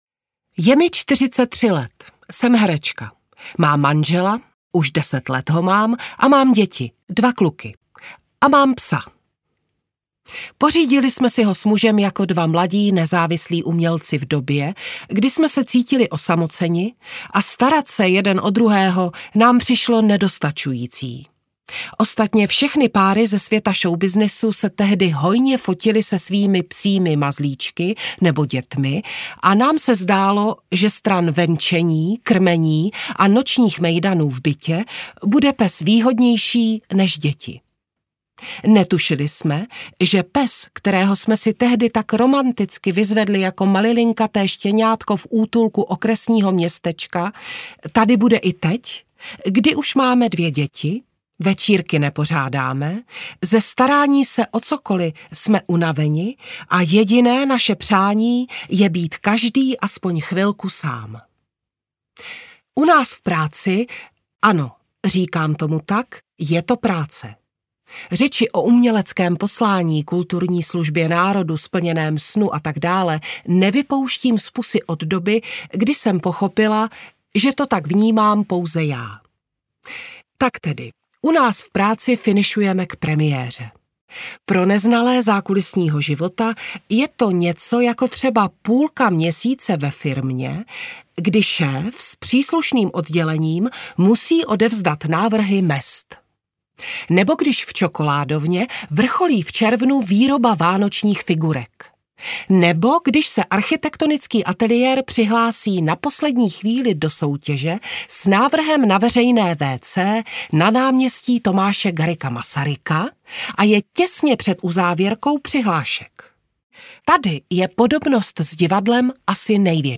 Jak jsem nebyla na lvech audiokniha
Ukázka z knihy
• InterpretMartina Preissová, Martin Preiss